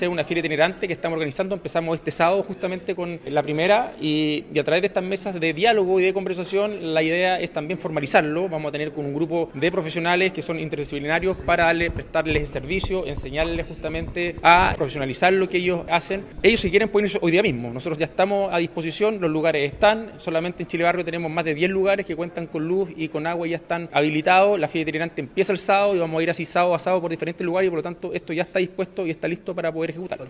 Mientras que el alcalde, Rodrigo Wainraihgt, afirmó que ya comenzó a operar la feria itinerante en la que pueden participar los comerciantes que estén formalizados.
rodrigo-wainraihgt-alcalde-cuna-2.mp3